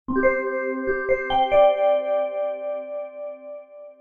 Сигнал на СМС